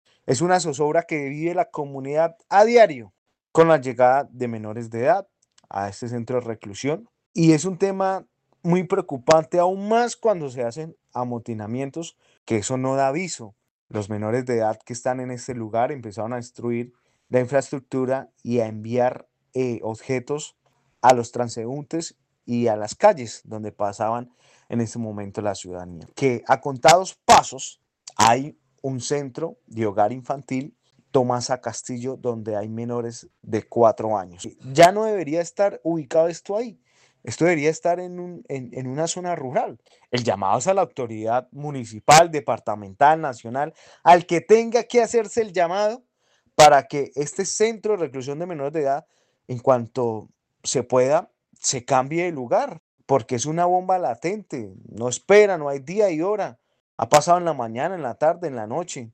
Larry Sánchez, edil comuna 5 de Bucaramanga